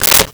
Switchboard Telephone Receiver Down 02
Switchboard Telephone Receiver Down 02.wav